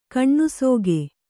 ♪ kaṇṇusōge